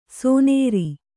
♪ sōnēri